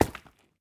minecraft / sounds / step / coral6.ogg
coral6.ogg